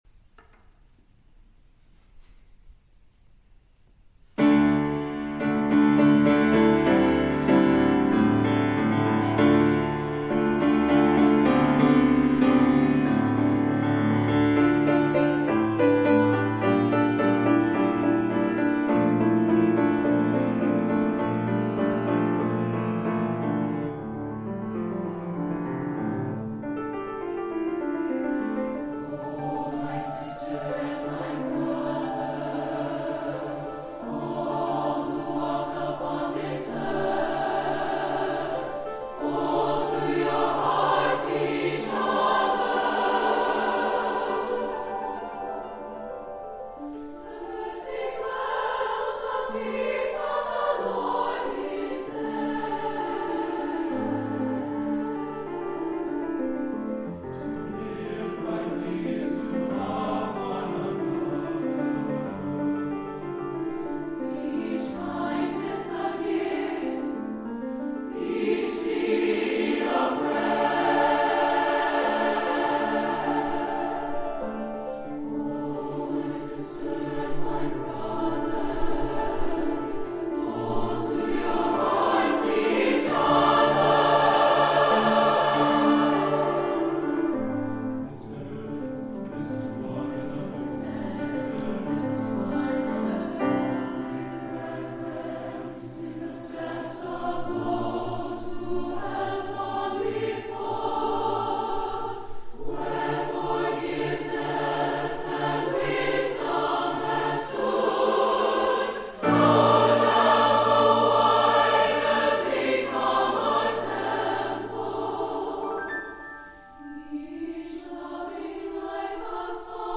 SATB version